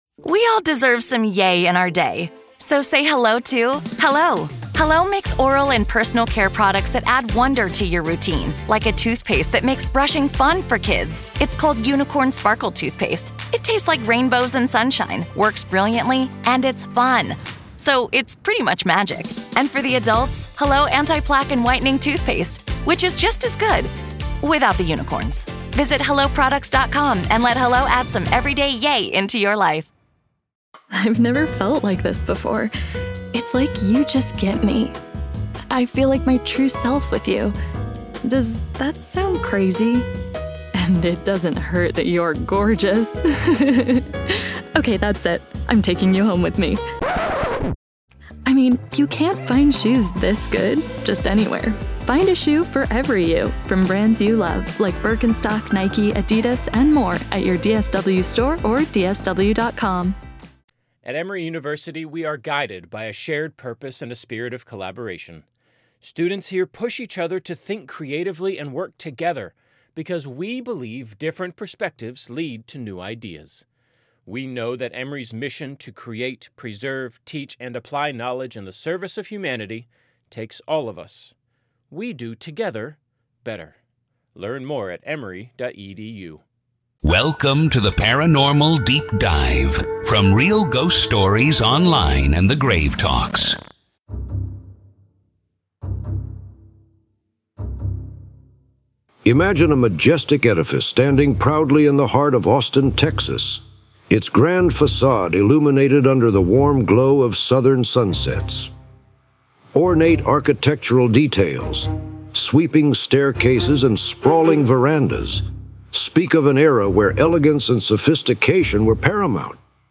In this episode, we delve deep into the rich history of the Driskill Hotel, exploring its origins, architectural splendor, and the pivotal events that have shaped its legacy. Through compelling storytelling and expert interviews, we uncover the chilling tales of restless spirits said to roam its corridors.